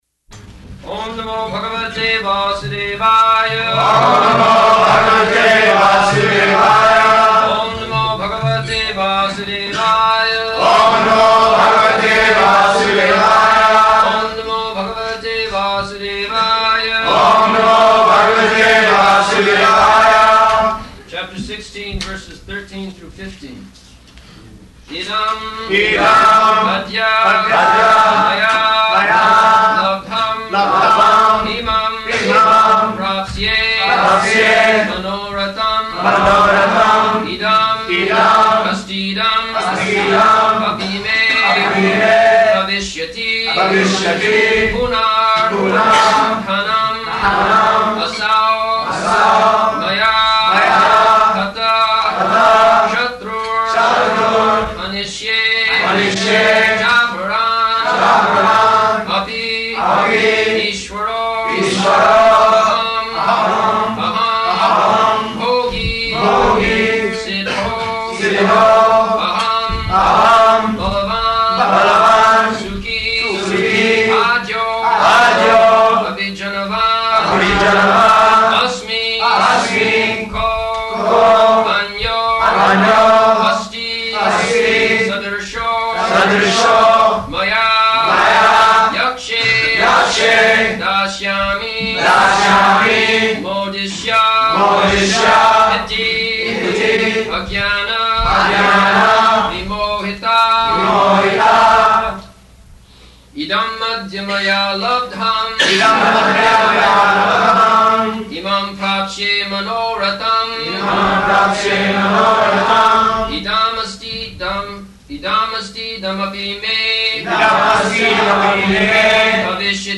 February 8th 1975 Location: Honolulu Audio file
[devotees repeat] Chapter Sixteen, verses 13 through 15. [leads chanting of verses, etc.]